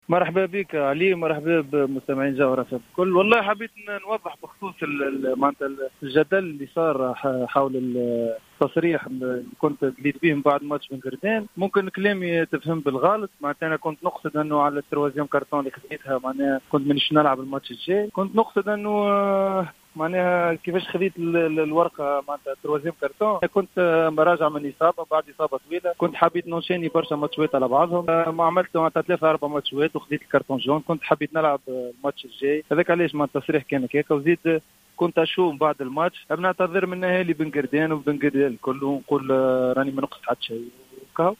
قدم لاعب النجم الساحلي ايهاب المساكني اعتذاراته الى اهالي بن قردان عشية اليوم الخميس 04 فيفري 2021 في تصريح خاص بجوهرة افم على اثر التصريح الذي قام به سابقا في مباراة النجم و الملعب التونسي.